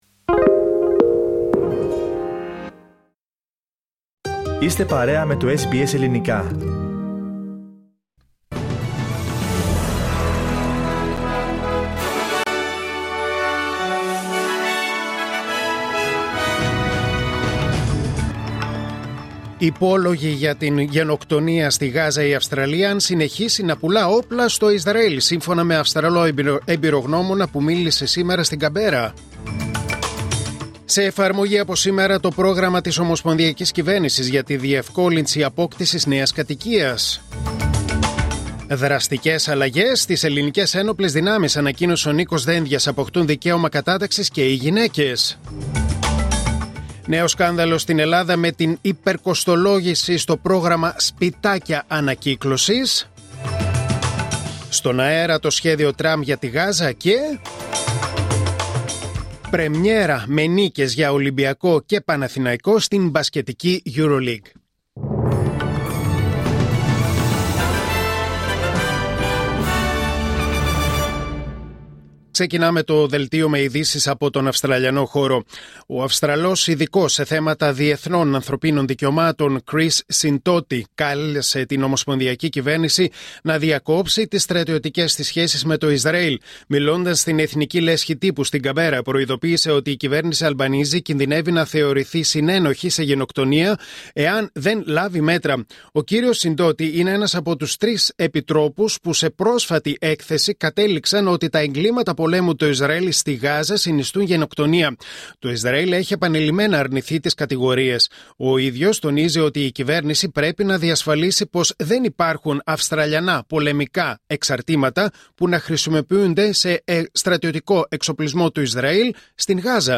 Δελτίο Ειδήσεων Τετάρτη 01 Οκτωβρίου 2025